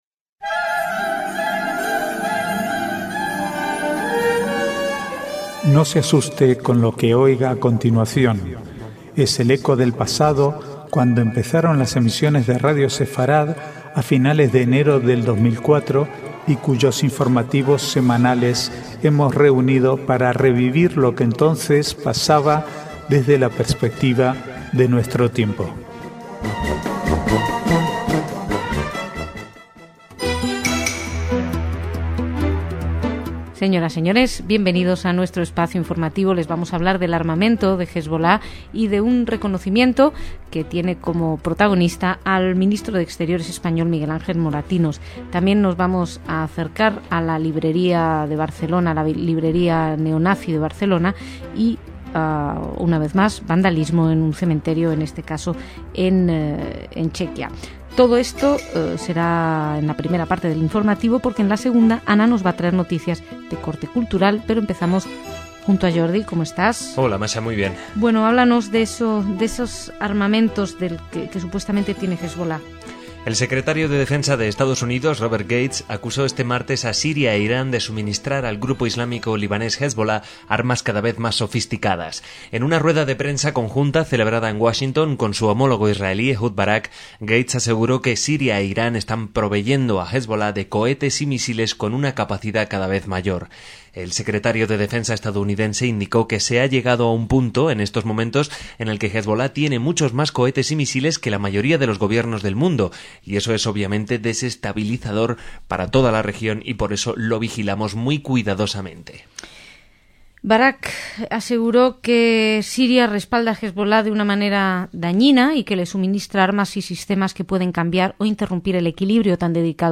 Archivo de noticias del 29/4 al 5/5/2010